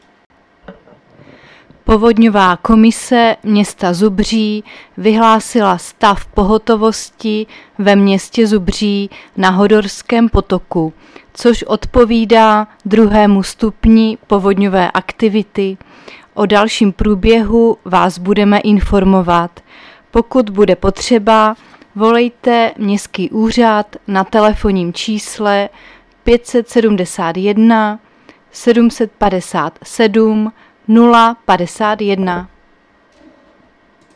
Záznam hlášení místního rozhlasu 14.9.2024